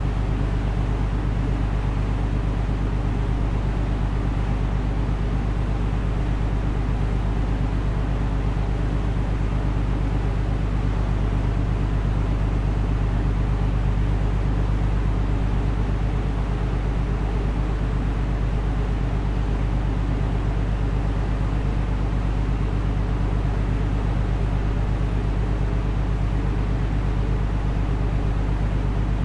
诺曼底 " 3号渡轮的发动机
Tag: 轮渡 电机 柴油机 发动机